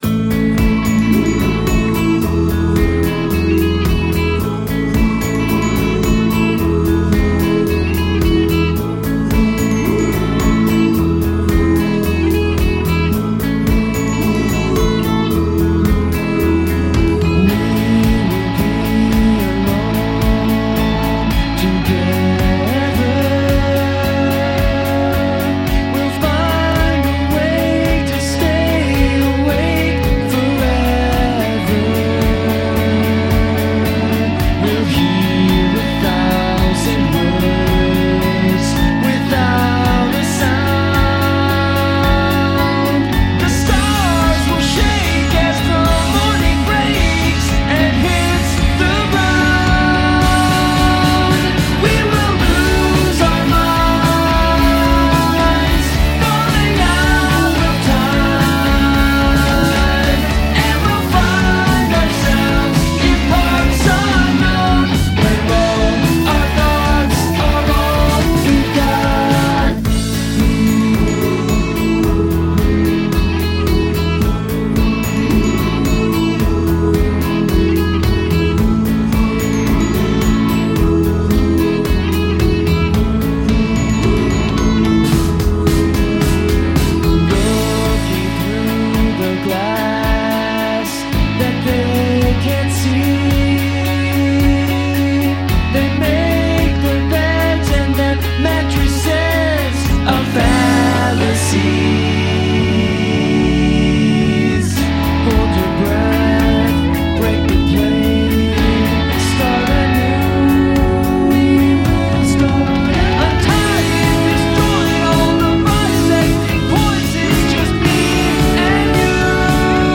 indie-rock band